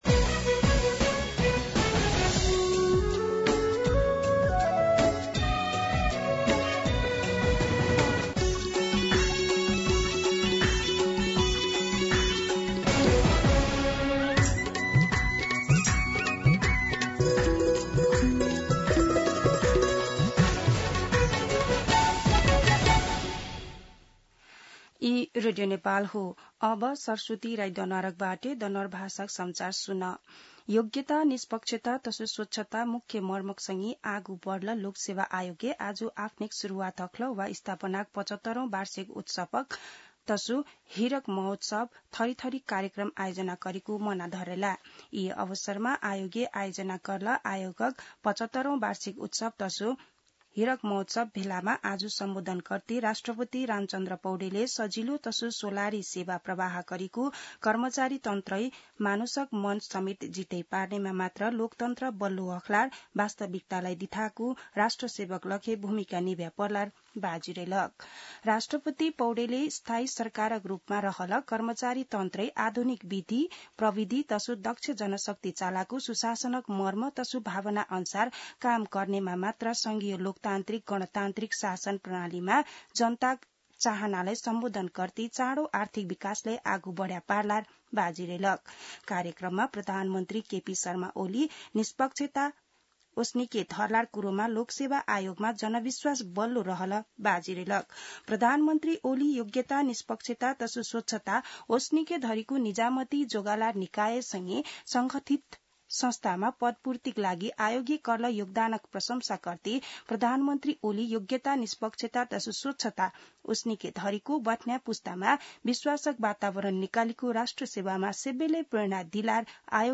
दनुवार भाषामा समाचार : १ असार , २०८२
Danuwar-News-01.mp3